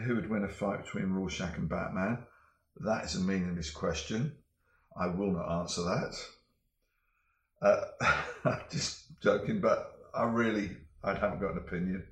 Dave Gibbons interview: Who'd win in a fight between Rorschach and Batman?